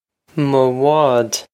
mo bhád muh wawd
Pronunciation for how to say
This is an approximate phonetic pronunciation of the phrase.